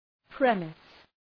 Προφορά
{‘premıs}